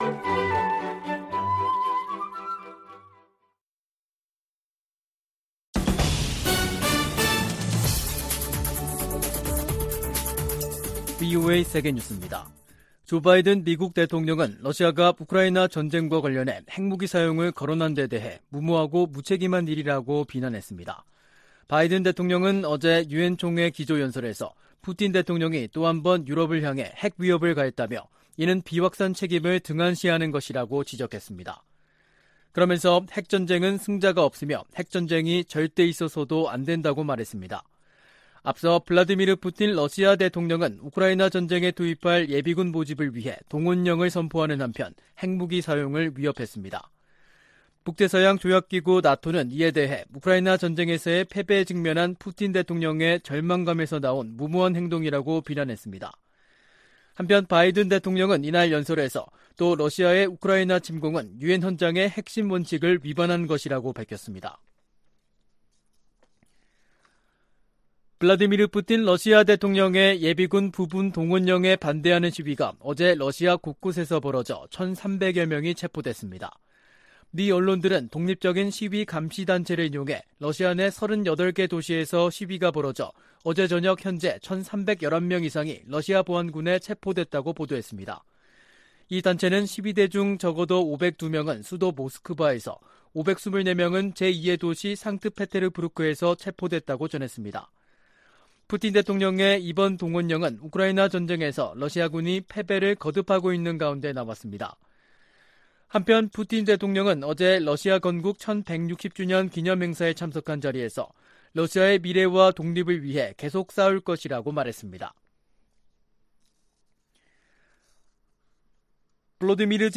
VOA 한국어 간판 뉴스 프로그램 '뉴스 투데이', 2022년 9월 22일 2부 방송입니다. 조 바이든 대통령과 윤석열 한국 대통령이 유엔총회가 열리고 있는 뉴욕에서 만나 북한 정권의 위협 대응에 협력을 재확인했습니다. 한일 정상도 뉴욕에서 대북 협력을 약속하고, 고위급 외교 채널을 통해 양국 관계 개선 노력을 가속화하기로 합의했습니다. 바이든 대통령은 유엔총회 연설에서 북한의 지속적인 유엔 제재 위반 문제를 지적했습니다.